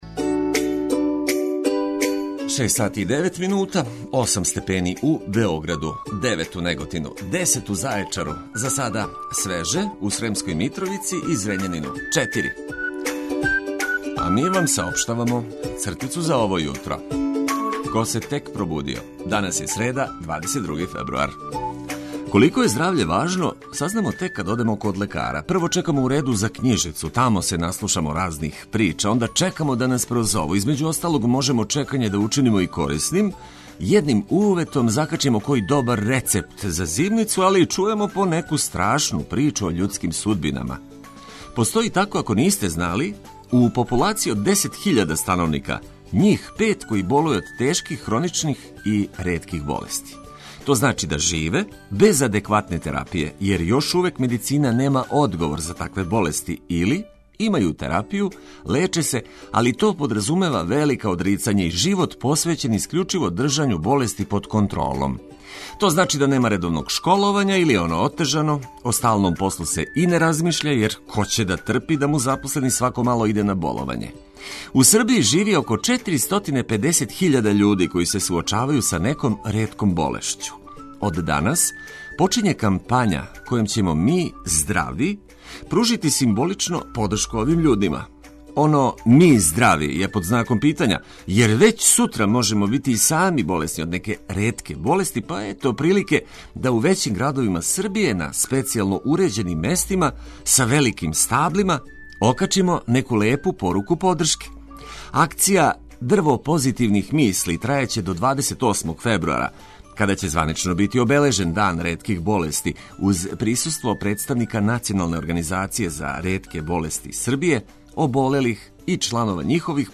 Свеже информације из прве руке уз брзи ритам музике за најлепше буђење.